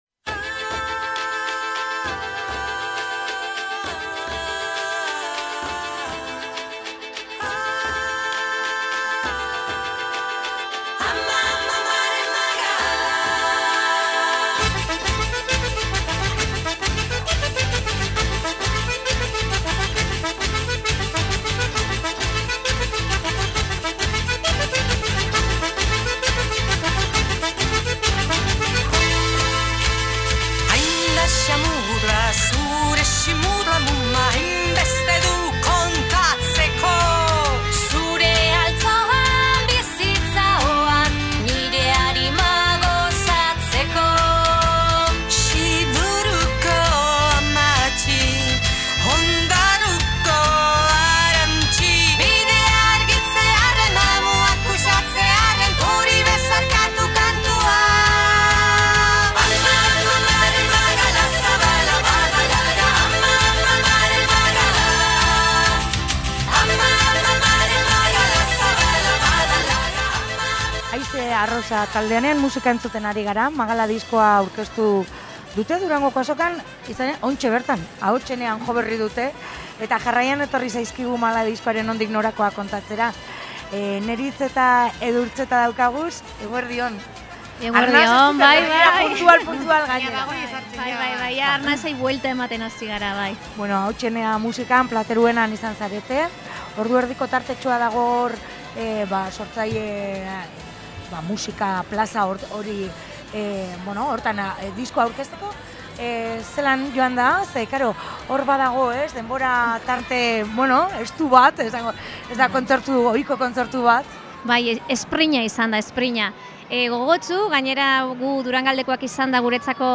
Proiektu honen jatorriaz eta bidetik datozenei buruz hitz egingo dugu orain taldearekin.